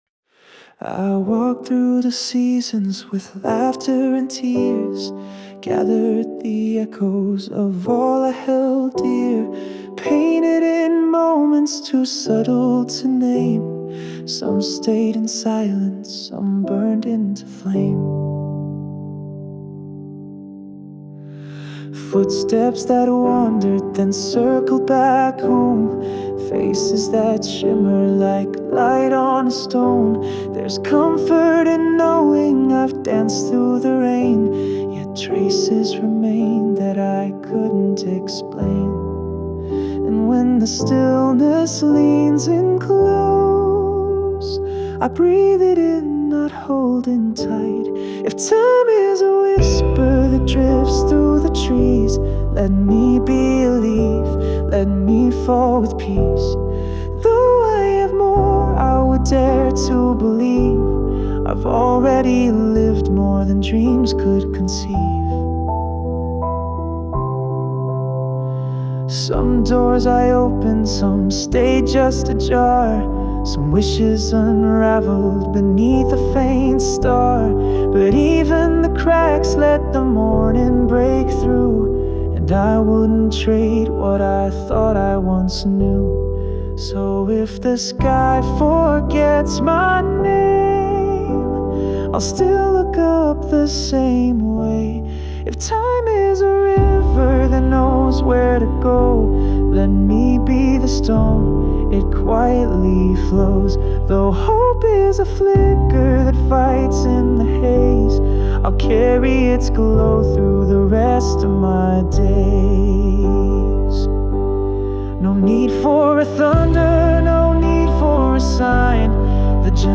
洋楽男性ボーカル著作権フリーBGM ボーカル
著作権フリーオリジナルBGMです。
男性ボーカル（洋楽・英語）曲です。